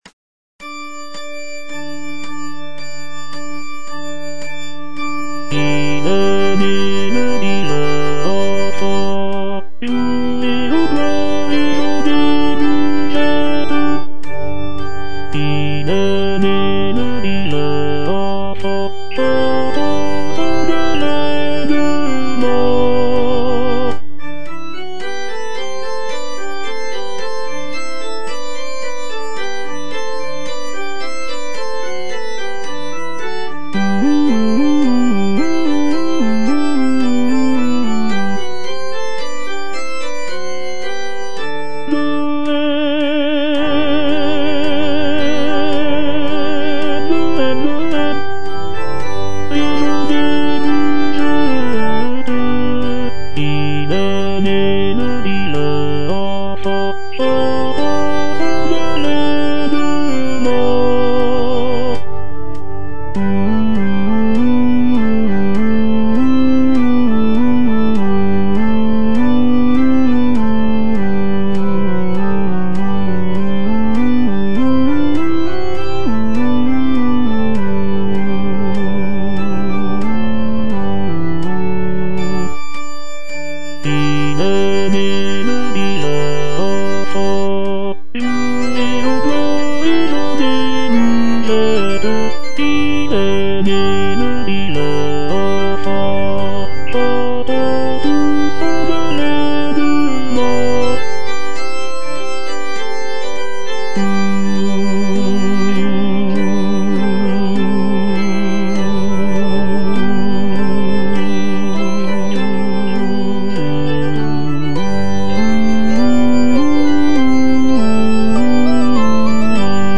- IL EST NÉ, LE DIVIN ENFANT Tenor I (Voice with metronome) Ads stop: auto-stop
It is a joyful and lively song that celebrates the birth of Jesus Christ.